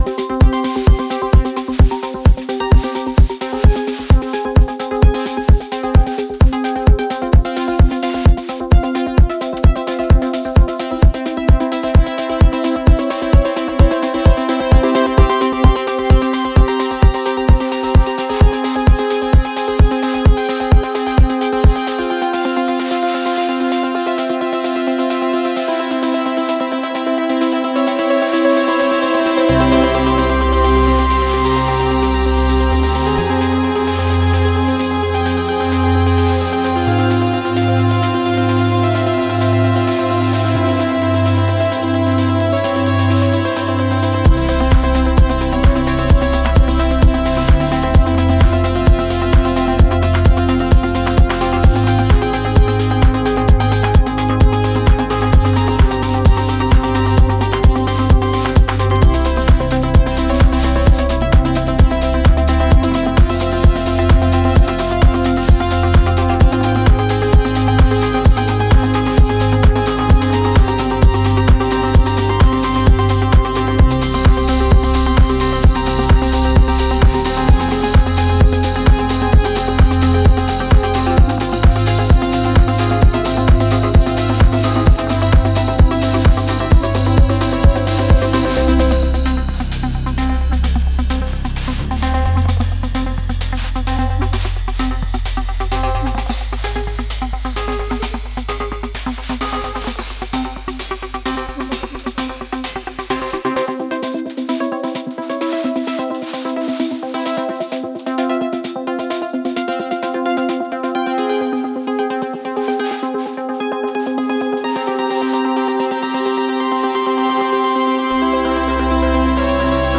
キャッチーなフックも満載のポスト・ベース最前線です。